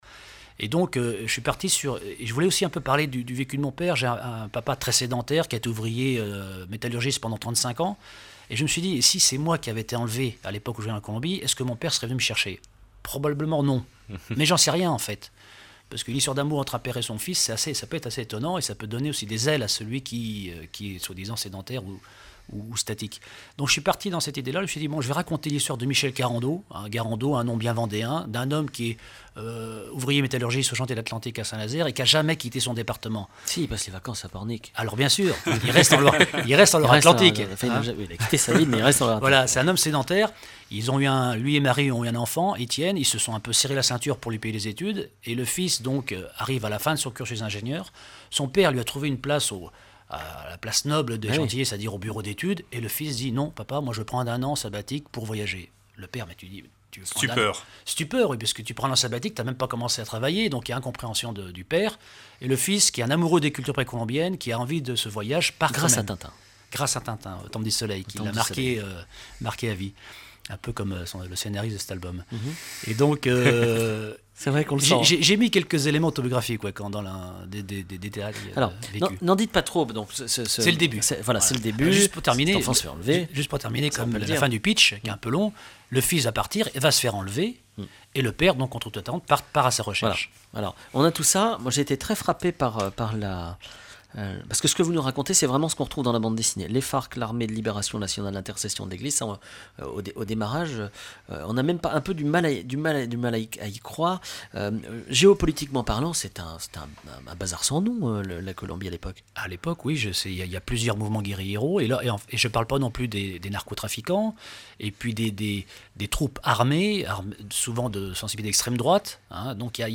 Emissions de la radio RCF Vendée
Catégorie Témoignage